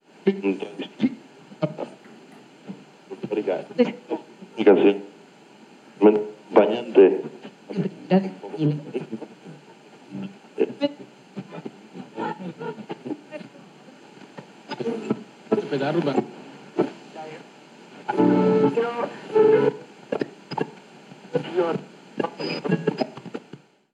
Búsqueda de emisora en una radio 2
Sonidos: Comunicaciones
Receptor de radio